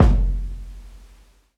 live_kick_10.wav